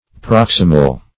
Proximal \Prox"i*mal\, a.